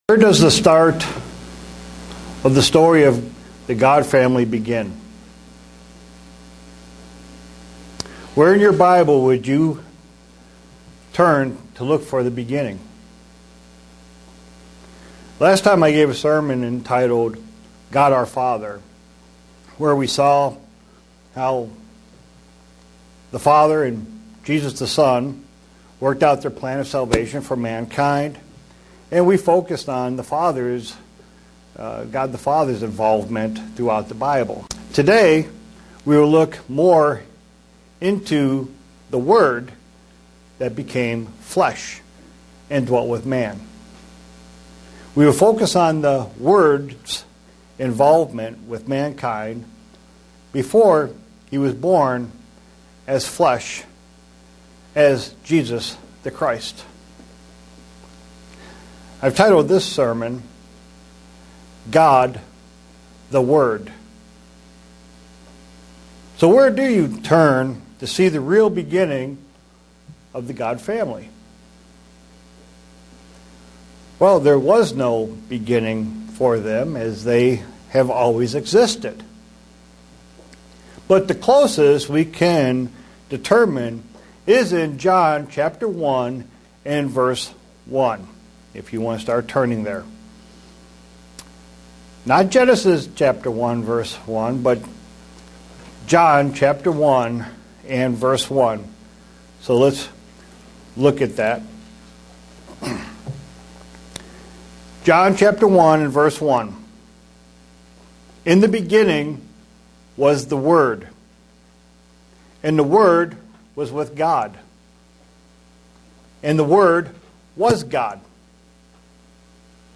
Given in Buffalo, NY
Print The "Word" became Flesh and how this involves Humankind. sermon Studying the bible?